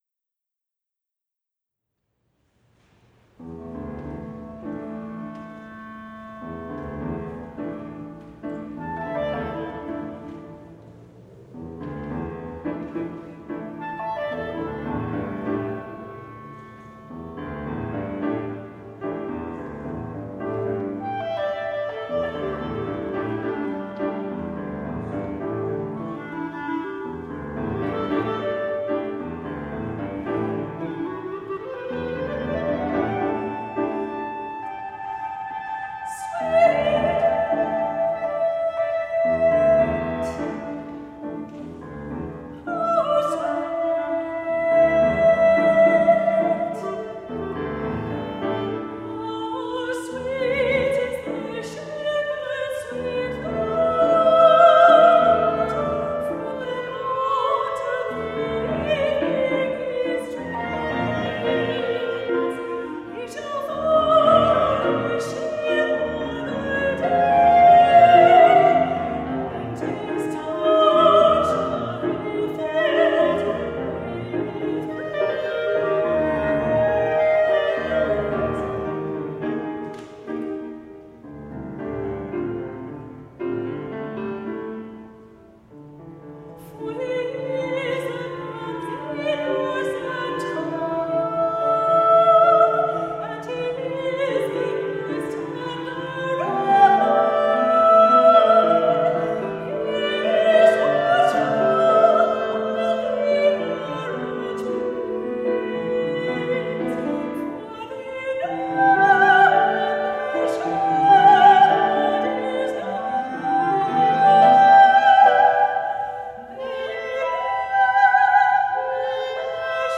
soprano, clarinet, piano [c. 12:00]
this set of three songs (with an Intermezzo for piano)